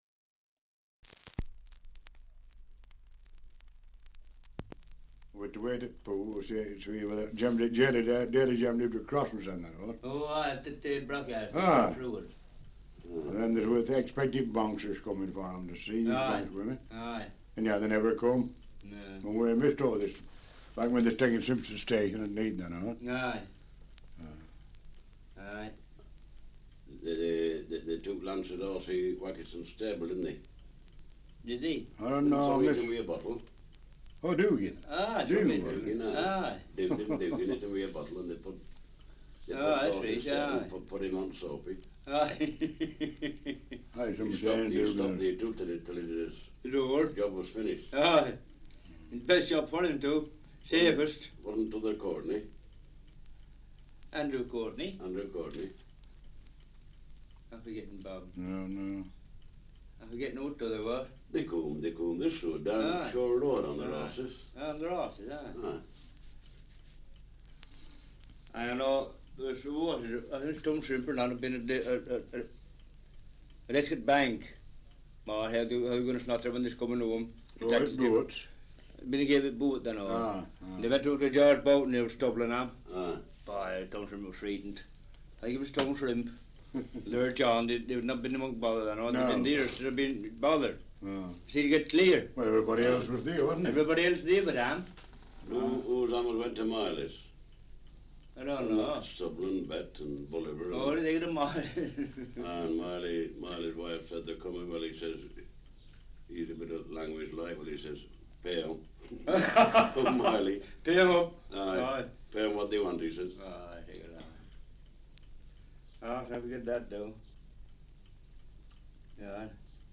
Survey of English Dialects recording in Marshside, Lancashire
78 r.p.m., cellulose nitrate on aluminium
English Language - Dialects